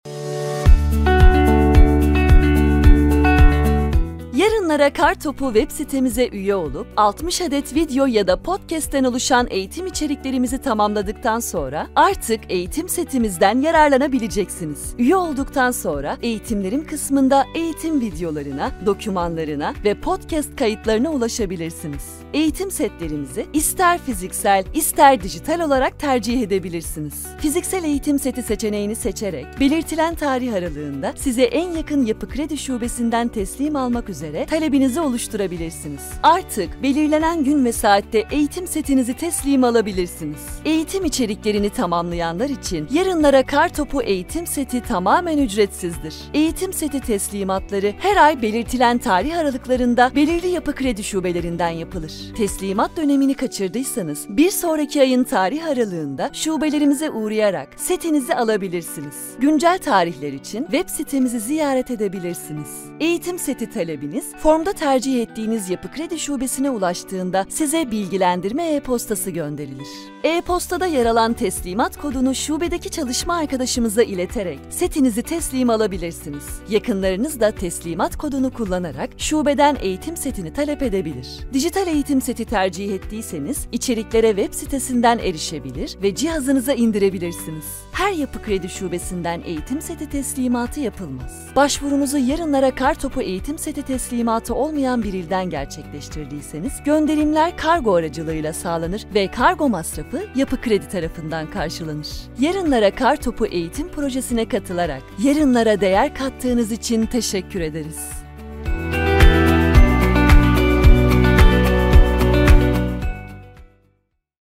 Profundo, Natural, Cool, Cálida, Empresarial
Explicador
She has her own professional home studio.